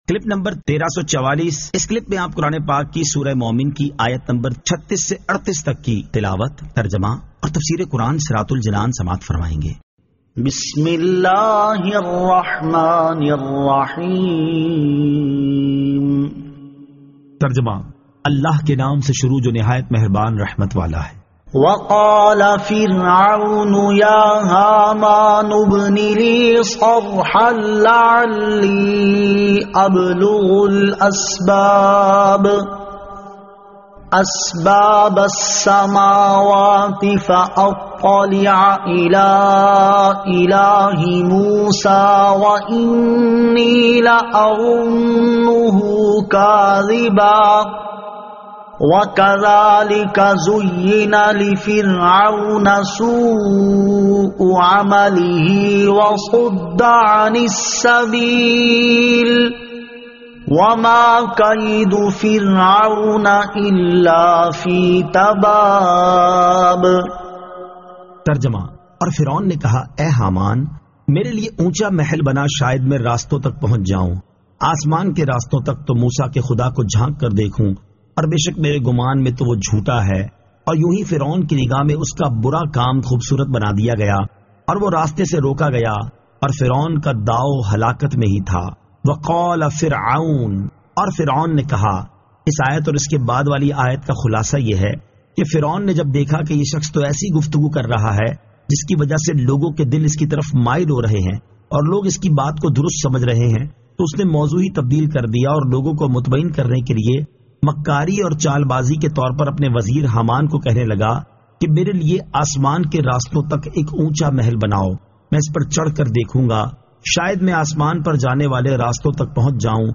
Surah Al-Mu'min 36 To 38 Tilawat , Tarjama , Tafseer